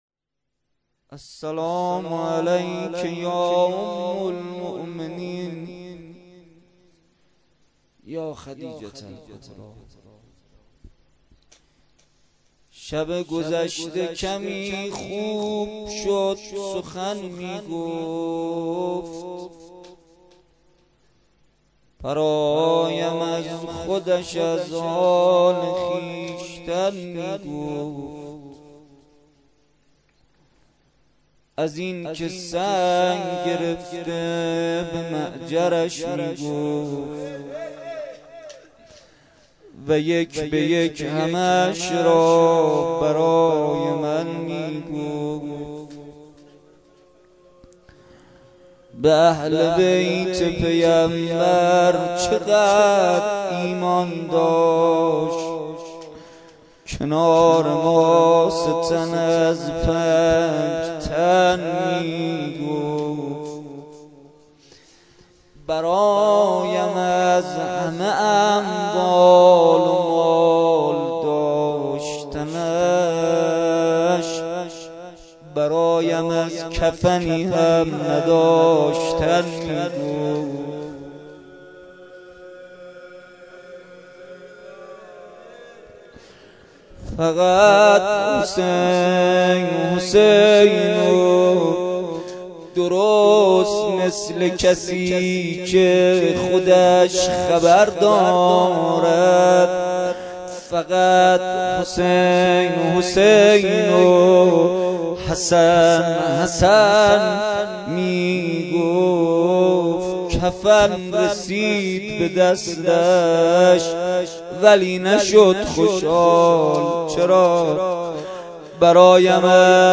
شب دهم ماه رمضان با مداحی کربلایی محمدحسین پویانفر در ولنجک – بلوار دانشجو – کهف الشهداء برگزار گردید.
دعا و مناجات روضه لینک کپی شد گزارش خطا پسندها 0 اشتراک گذاری فیسبوک سروش واتس‌اپ لینکدین توییتر تلگرام اشتراک گذاری فیسبوک سروش واتس‌اپ لینکدین توییتر تلگرام